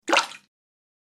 دانلود آهنگ آب 47 از افکت صوتی طبیعت و محیط
دانلود صدای آب 47 از ساعد نیوز با لینک مستقیم و کیفیت بالا
جلوه های صوتی